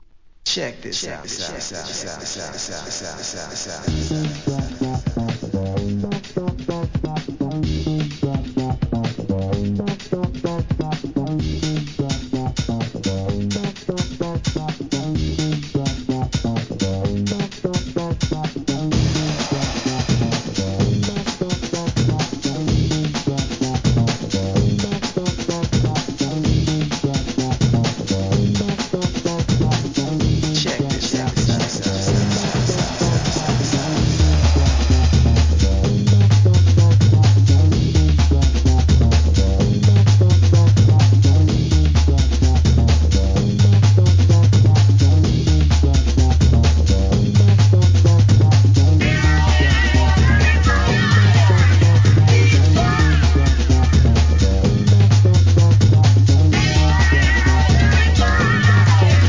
HIP HOP/R&B
軽快にはじける序盤から303が暴走する終盤まで、息つく暇のない超強力アシッド・ブレイクビーツ！